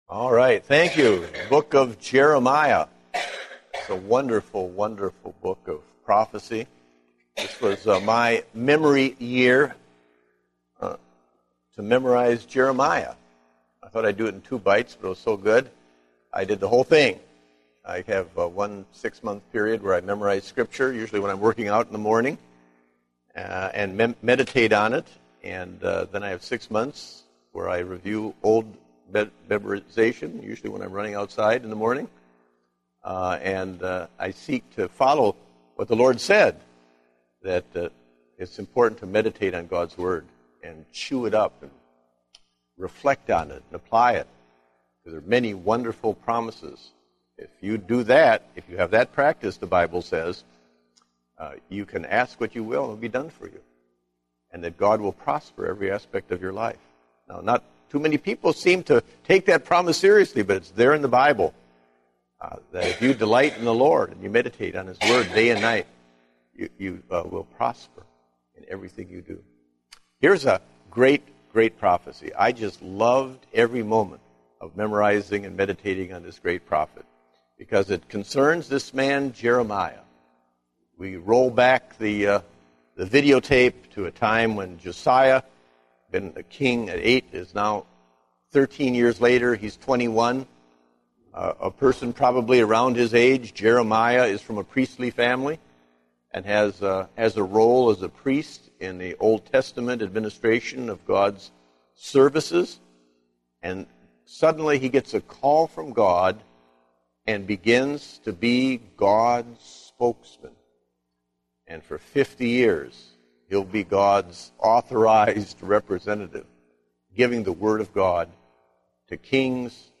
Date: April 4, 2010 (Adult Sunday School)